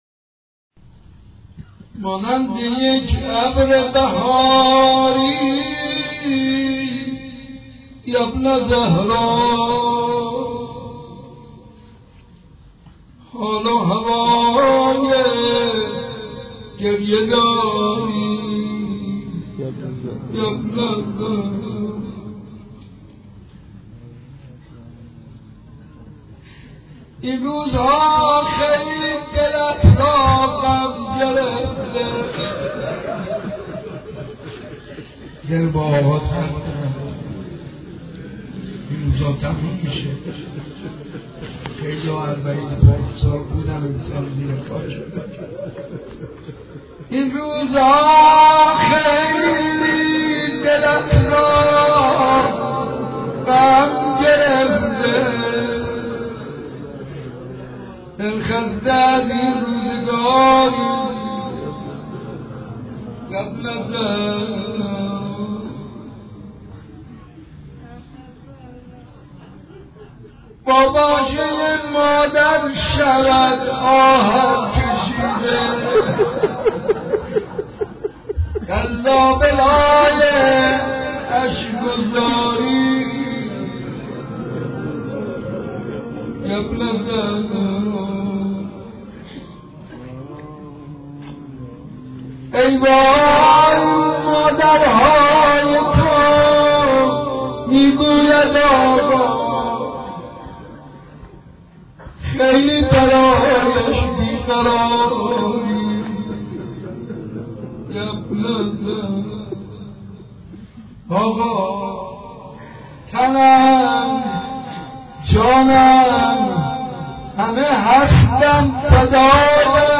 بشنوید/زیارت اربعین با نوای حاج منصور ارضی